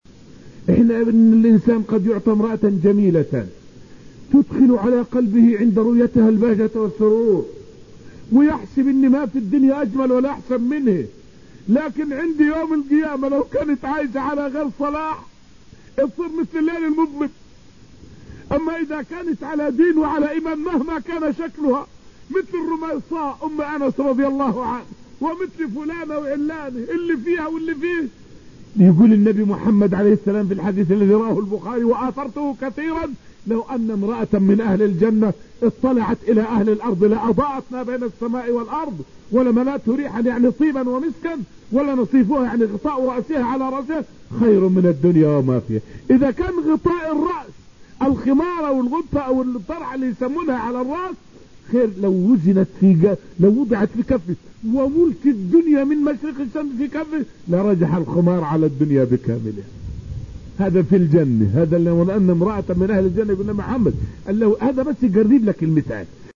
فائدة من الدرس الحادي عشر من دروس تفسير سورة الرحمن والتي ألقيت في المسجد النبوي الشريف حول ذكر جمال وحسن نساء الجنة.